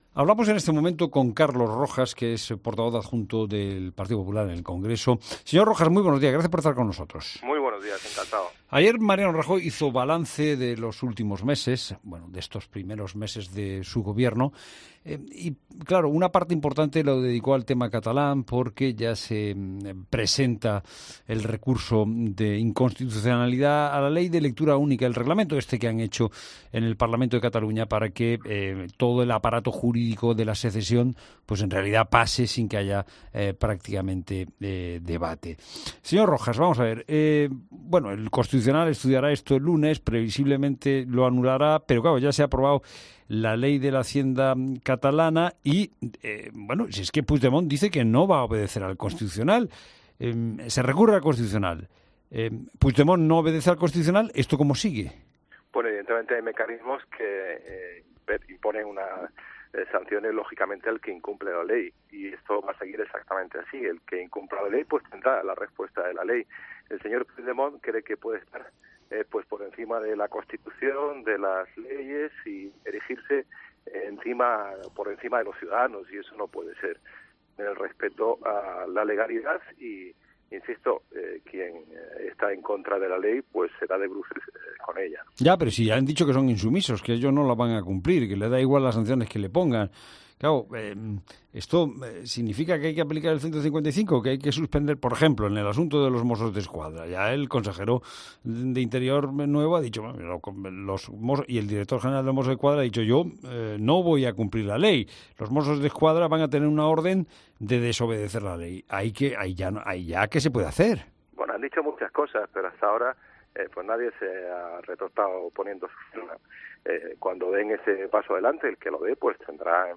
Carlos Rojas, portavoz adjunto del PP en el Congreso, en "La Mañana Fin de Semana"
Entrevista política